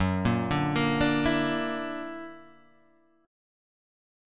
Gb+7 chord